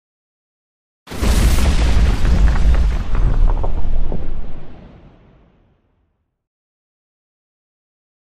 Explosion Low Fire Destruction Version 1 - Light Glass And Pipe